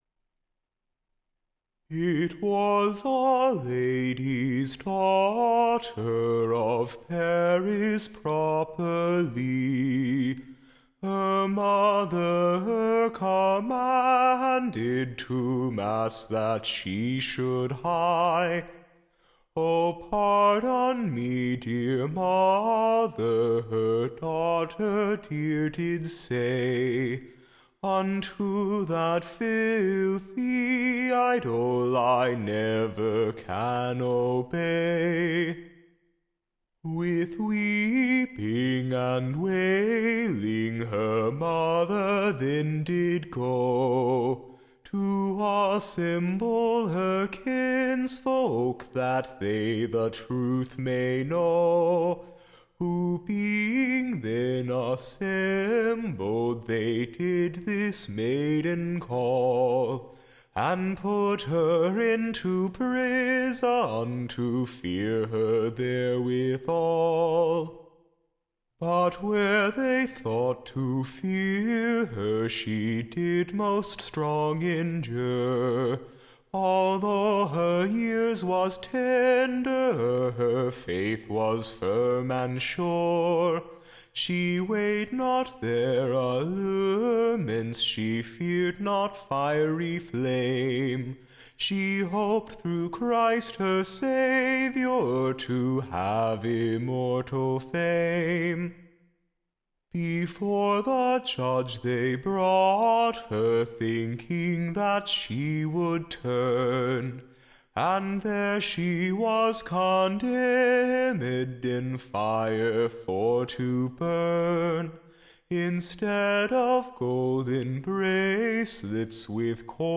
Recording Information Ballad Title A Rare Example of a Vertuous Maid in Paris.